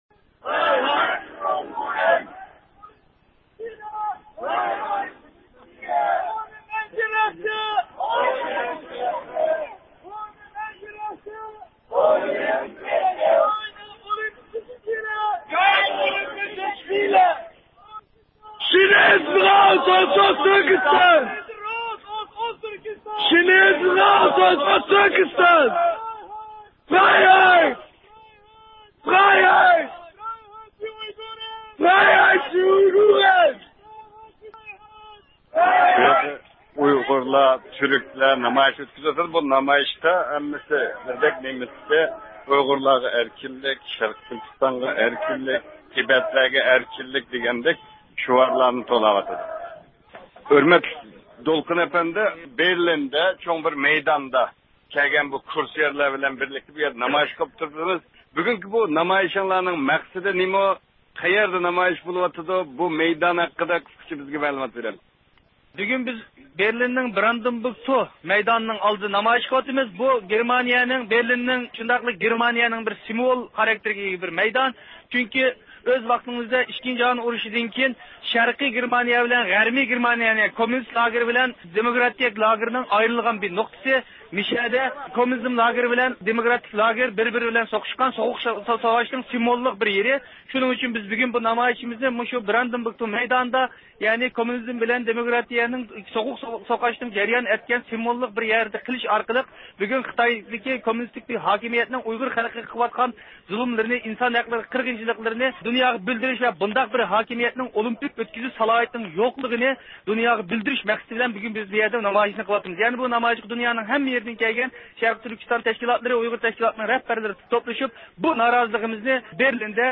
بىز بۇ نامايىشچىلارنىڭ كۆز قاراشلىرىنى ئېلىش ئۈچۈن ئۇلار بىلەن نەق مەيداندا سۆھبەت ئېلىپ باردۇق.